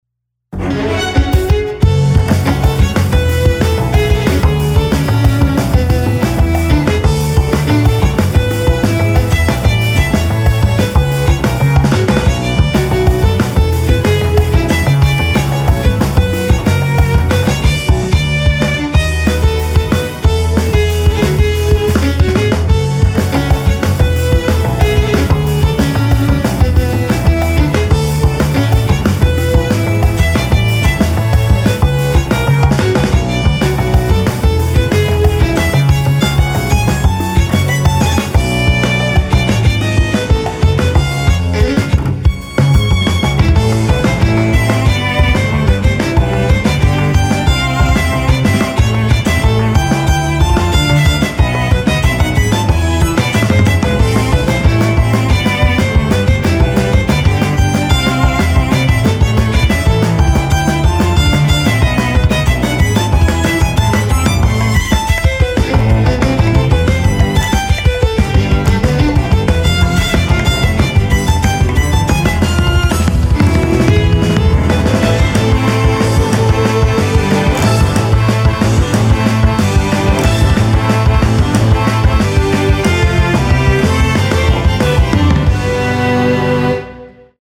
上記の楽曲のテンポを若干遅くした音源です。